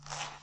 拉链4
描述：在带有Android平板电脑的浴室中录制，并使用Audacity进行编辑。